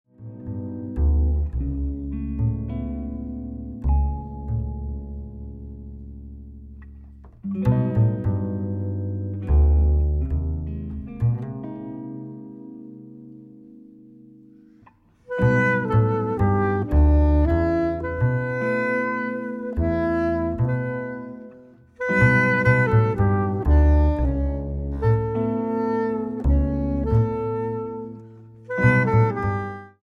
saxophone, bassclarinet
piano
guitar
drums, percussion